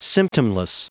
Prononciation du mot : symptomless